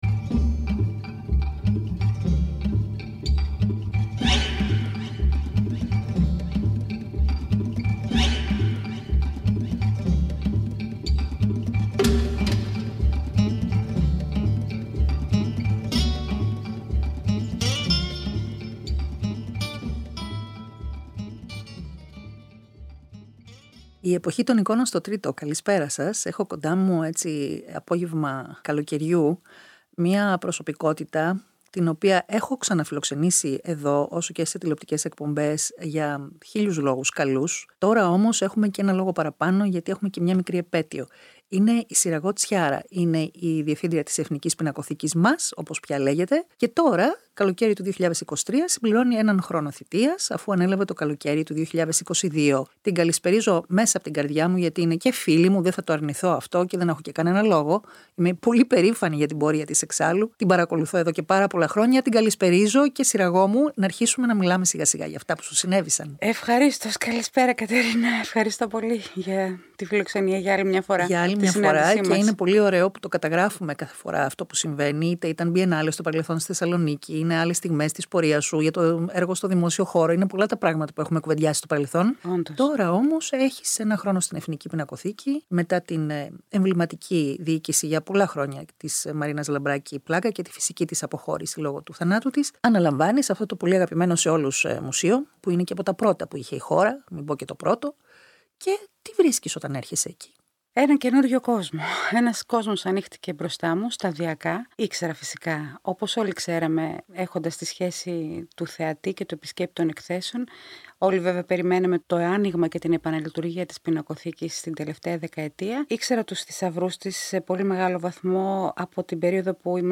Καλεσμένη η διευθύντρια της Εθνικής Πινακοθήκης Συραγώ Τσιάρα
Συνεντεύξεις